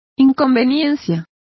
Complete with pronunciation of the translation of inconveniences.